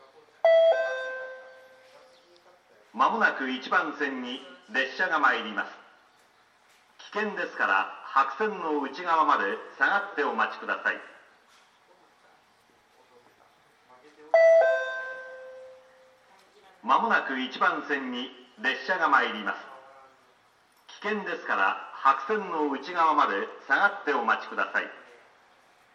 １番線しなの鉄道線
接近放送普通　軽井沢行き接近放送です。